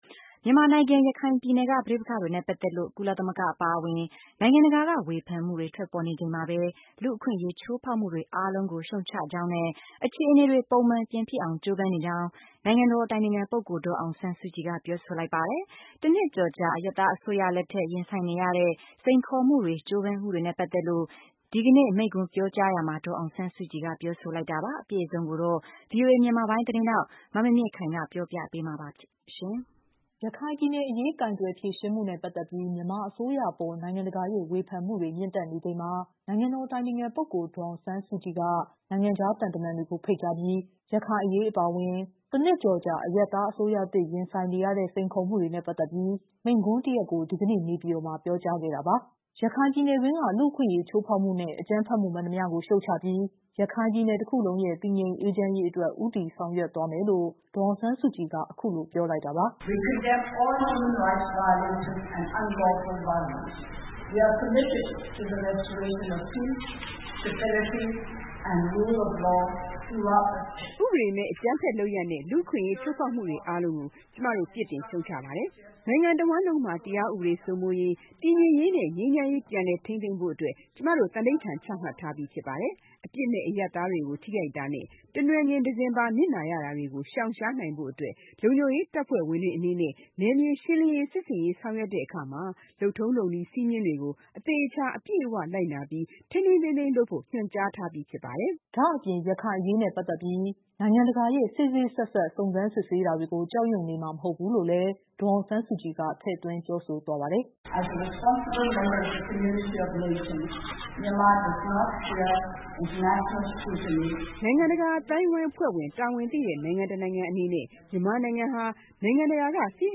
မြန်မာနိုင်ငံ ရခိုင်ပြည်နယ်က ပဋိပက္ခတွေနဲ့ ပတ်သက်လို့ ကုလသမဂ္ဂ အပါအဝင် နိုင်ငံတကာ က ေ၀ ဖန်မှုတွေ ထွက်ပေါ်နေချိန်မှာပဲ လူ့အခွင့်အရေး ချိုးဖောက်မှုတွေ အားလုံးကို ရှုတ်ချကြောင်းနဲ့ အခြေ အနေတွေ ပုံမှန်ပြန်ဖြစ်အောင် ကြိုးပမ်းနေကြောင်း နိုင်ငံတော် အတိုင်ပင်ခံပုဂ္ဂိုလ် ဒေါ်အောင်ဆန်းစု ကြည်က ပြောဆိုလိုက်ပါတယ်။ တနှစ်ကျော်ကြာ အရပ်သားအစိုးရလက်ထက် ရင်ဆိုင်နေရတဲ့ စိန်ခေါ် မှုတွေနဲ့ ပတ်သက်လို့ ကြိုးပမ်းမှုတွေနဲ့ ပတ်သက်လို့ ဒီကနေ့ မိန့်ခွန်းပြောကြားရာမှာ ဒေါ်အောင်ဆန်း စုကြည်က ပြောဆိုလိုက်တာပါ။